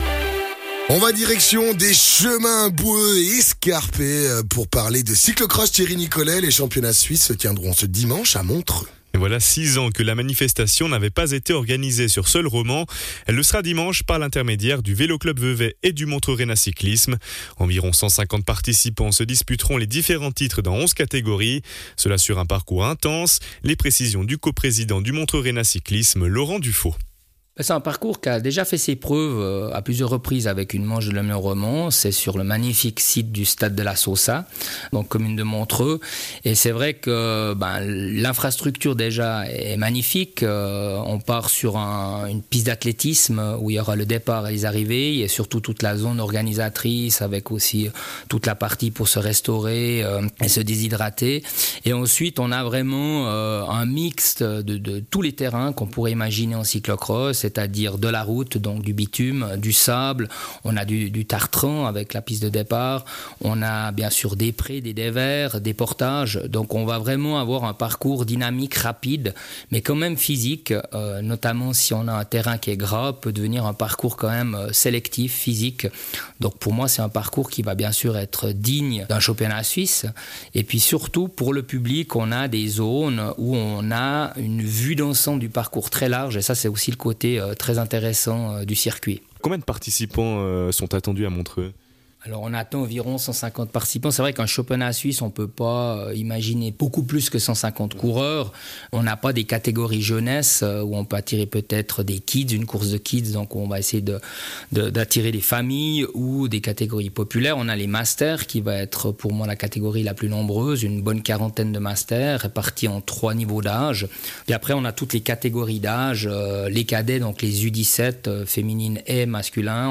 Intervenant(e) : Laurent Dufaux, co-président du Montreux-Rennaz Cyclisme